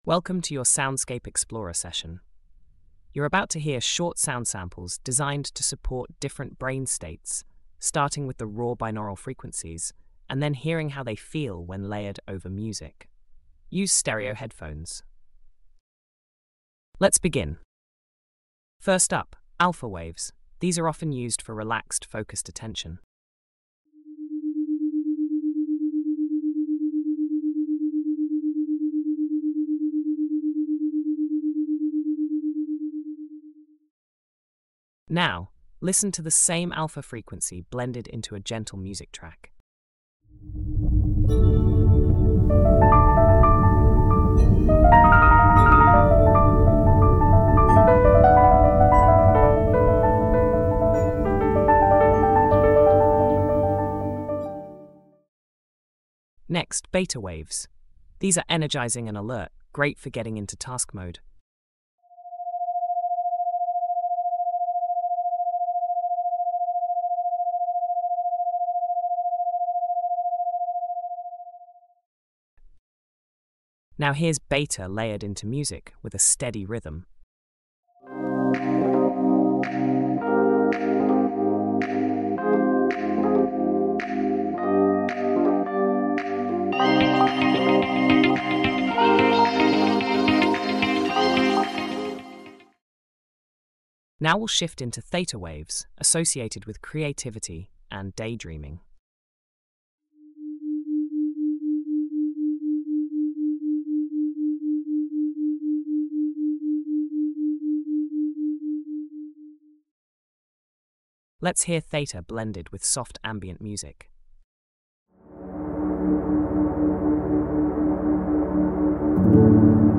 Focus Soundscape Explorer - Swivel
Pop on your headphones and explore how different sound frequencies can shift your mental state and support your focus.